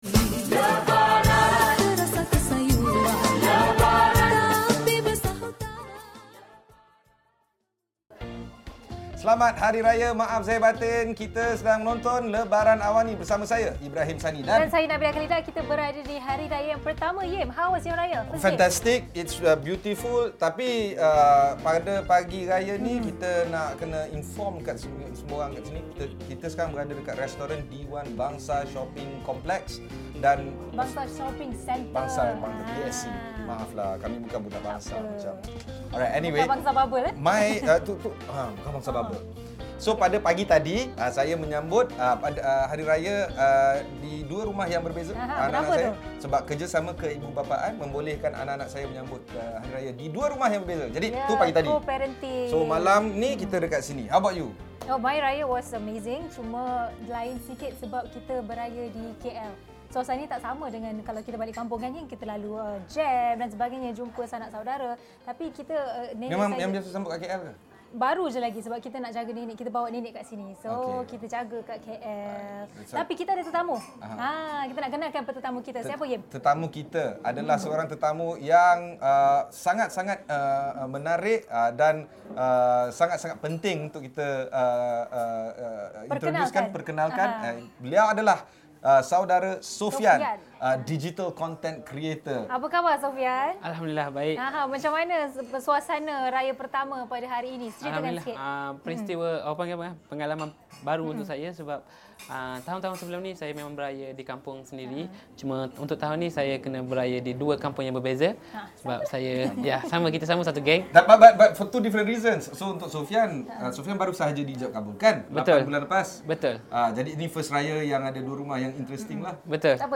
cerita Aidilfitri daripada peniaga & pengunjung Jalan Tuanku Abdul Rahman pada Hari Raya Pertama Lebaran Awani.